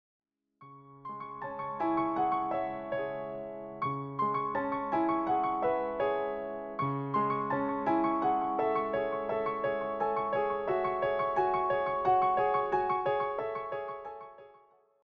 piano pieces
with simple lines that feel open and unforced.